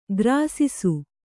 ♪ grāsisu